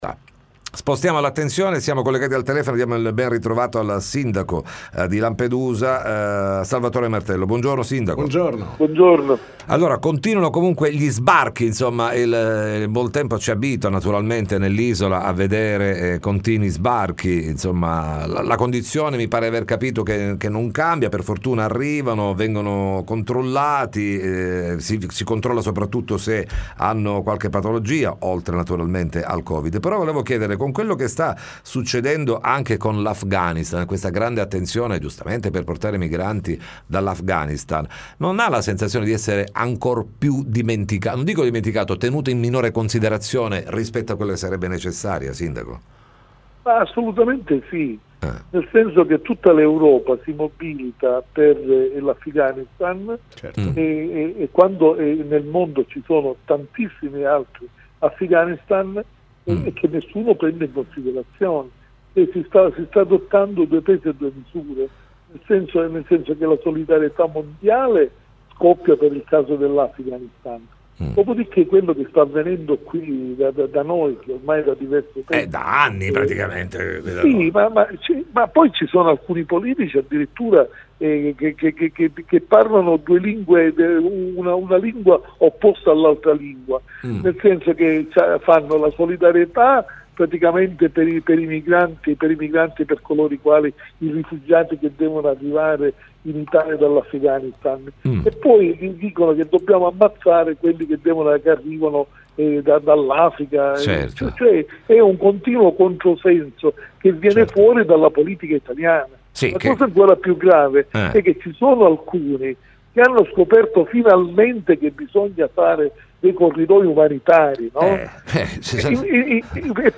TM intervista il sindaco Martello